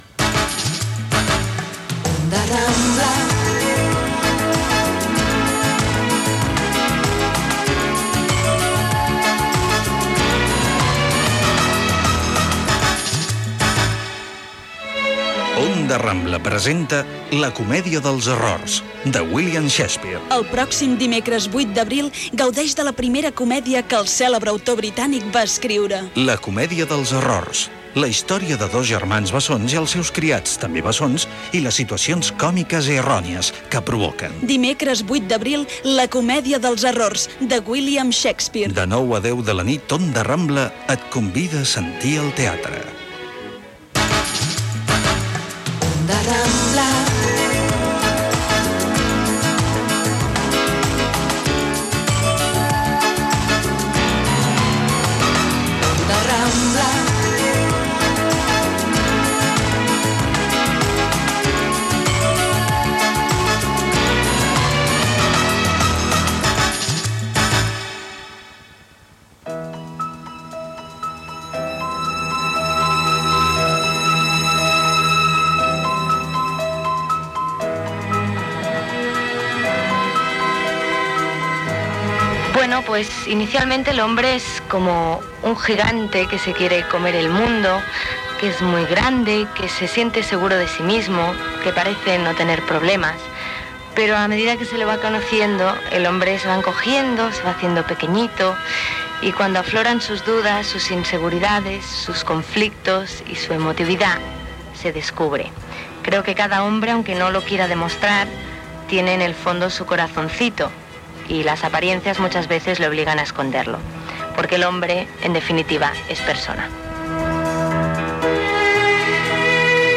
Indicatiu de l'emissora, promo "La comèdia dels errors", indicatiu, inici del programa amb el sumari i la presentació. Comentari sobre "Titànic". El diari d'un filòsof (Arsenio Corsellas),
Entreteniment